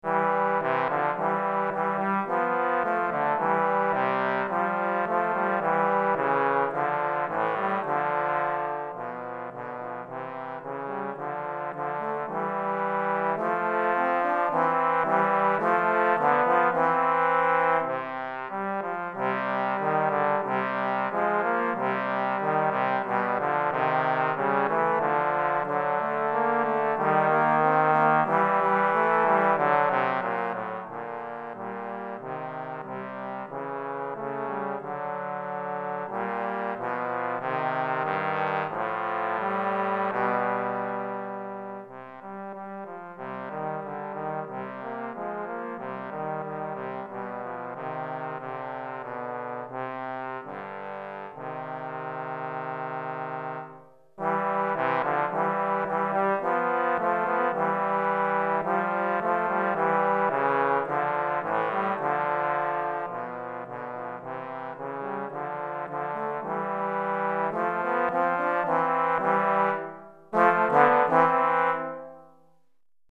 Pour 2 trombones DEGRE fin de cycle 1 Durée